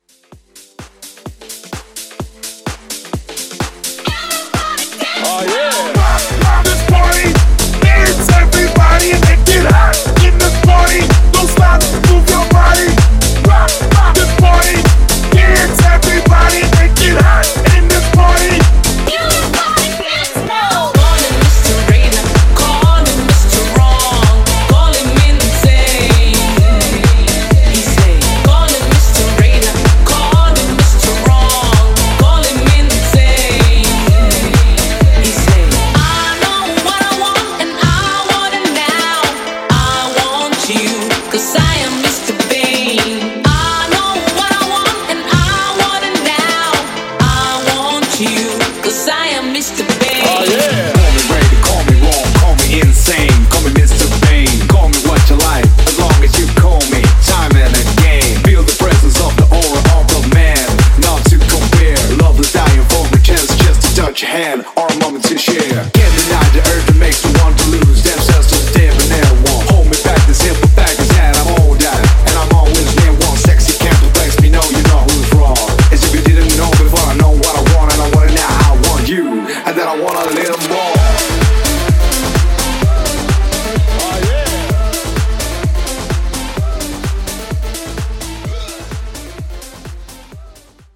60's